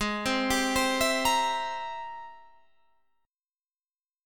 G#+M9 Chord